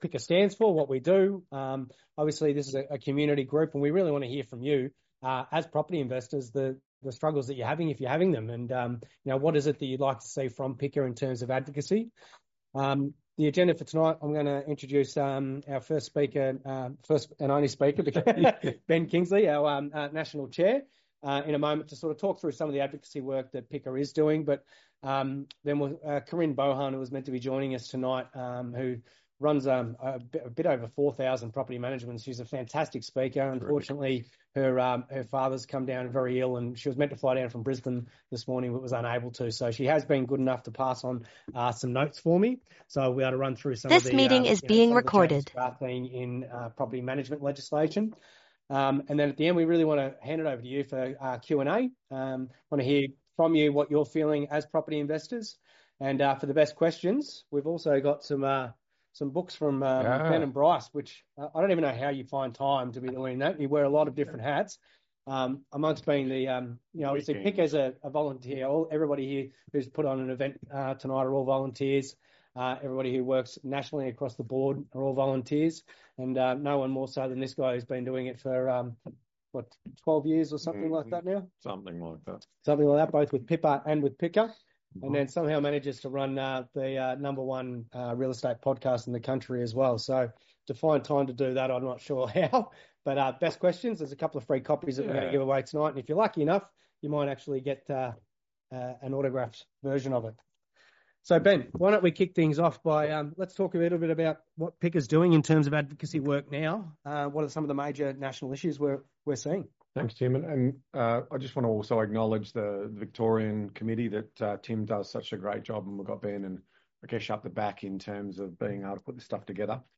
Melbourne, 21 May 2025 – The Property Investors Council of Australia (PICA) hosted a pivotal Information Sharing and Community Discussion event last night in Victoria , bringing together concerned property investors to unpack the ongoing legislative pressures reshaping the rental market and highlight the urgent need for balanced advocacy.
PICA-VIC-Meetup-1.mp3